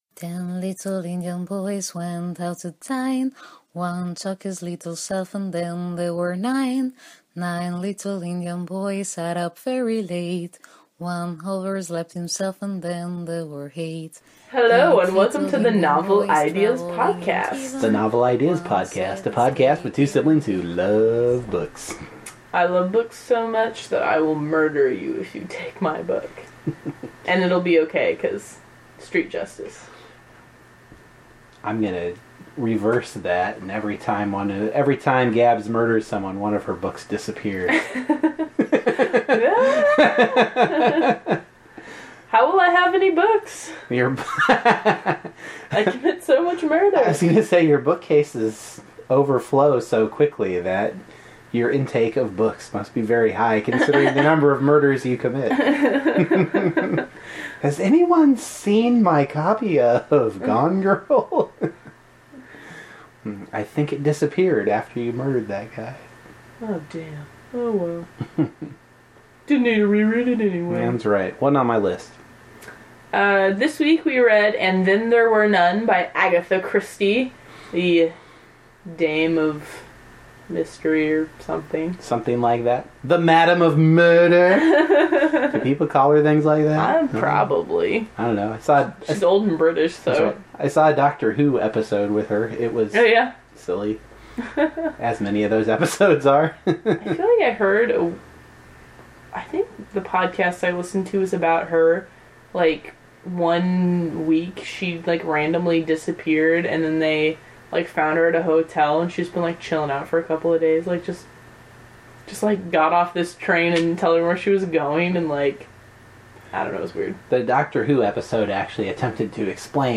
The music bump is the “Ten Little Indians” rhyme that the book uses as scaffolding for murder.